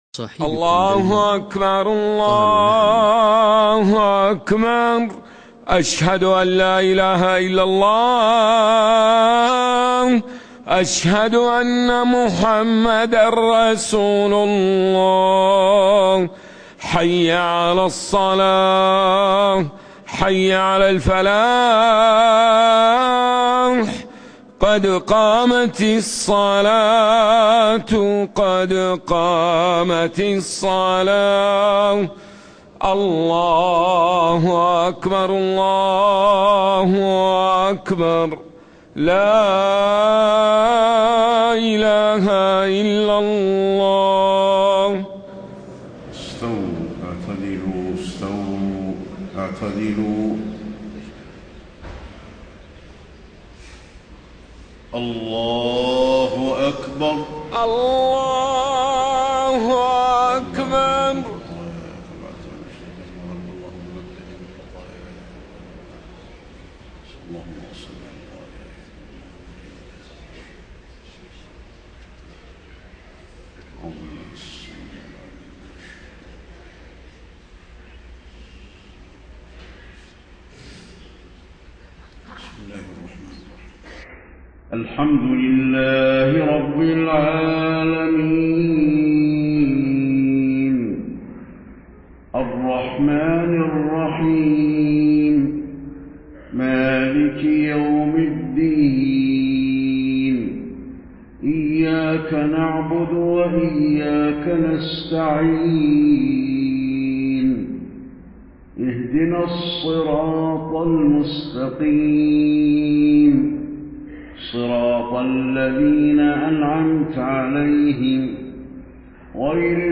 صلاة المغرب 2 - 4 - 1434هـ سورتي القارعة و الهمزة > 1434 🕌 > الفروض - تلاوات الحرمين